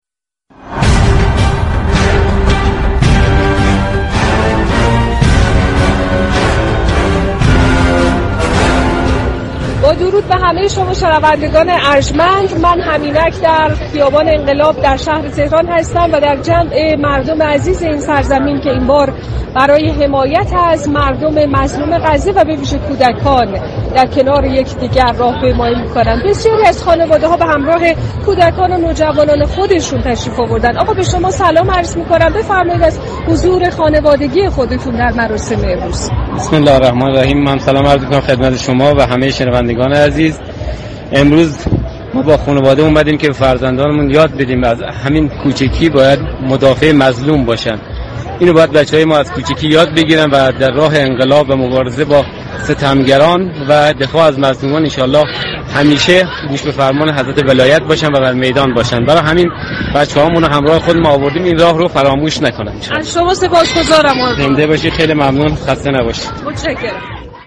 رویداد
ویژه برنامه «این خشم مقدس» منعكس كننده تجمع گسترده مردم است